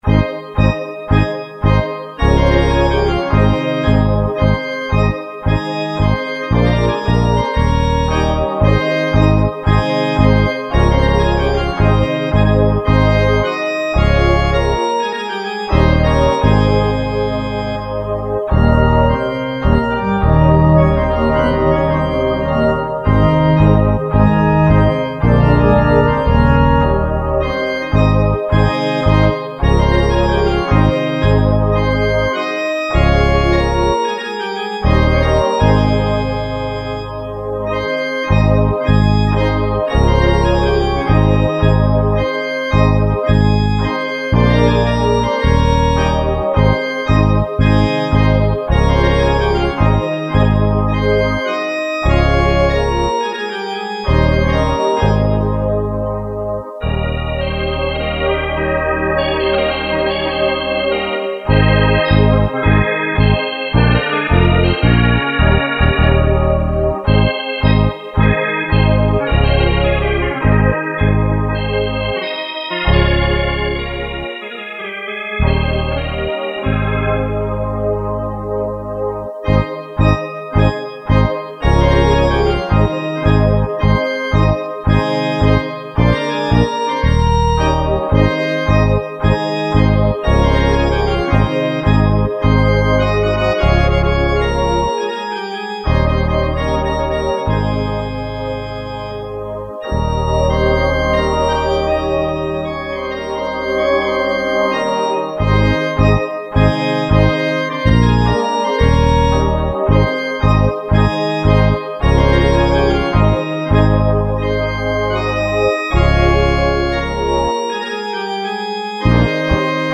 Acordeón #1: